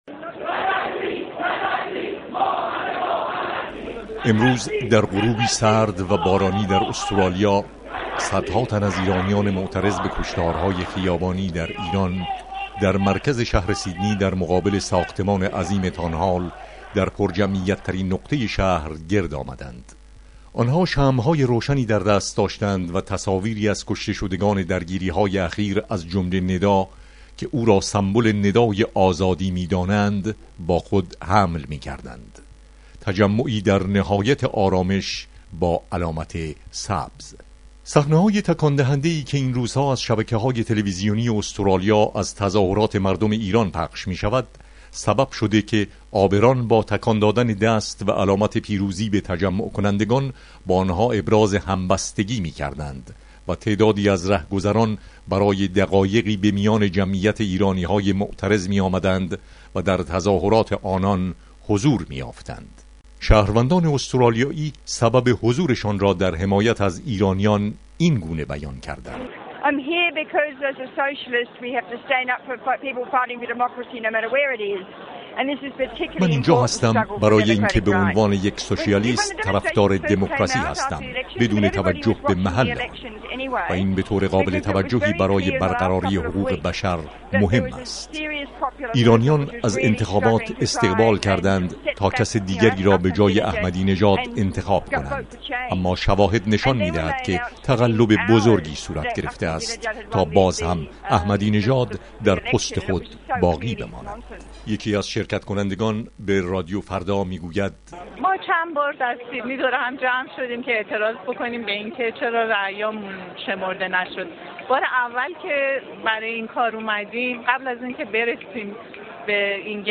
گزارش
از تجمع سیدنی